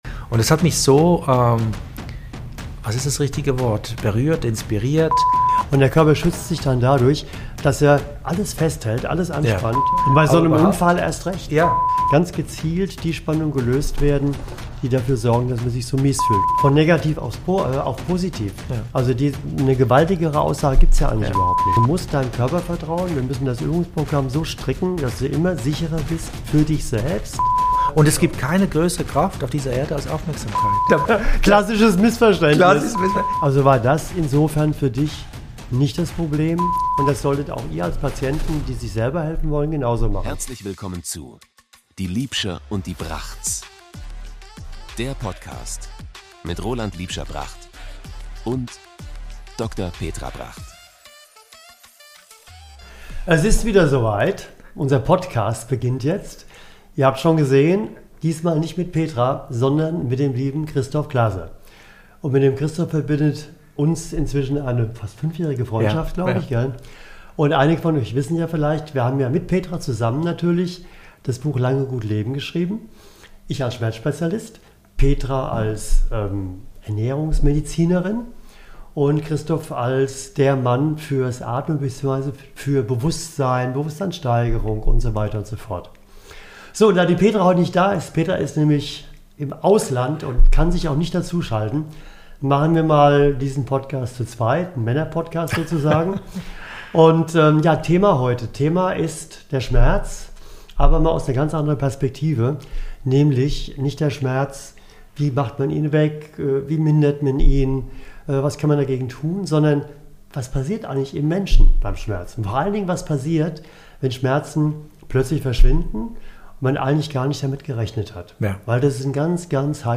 Ein tiefes, ehrliches Gespräch über Verantwortung, Veränderung und das Gefühl, endlich wieder im eigenen Körper zu Hause zu sein.